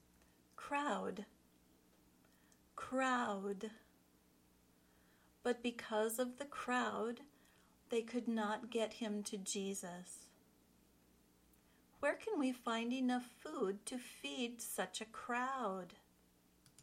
Then, listen to how it is used in the sample sentences.
kraʊd (noun)